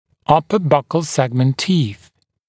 [‘ʌpə ˈbʌkl ‘segmənt tiːθ][ˈапэ ˈбакл ‘сэгмэнт ти:с]зубы бокового сегмента (-ов) верхнего зубного ряда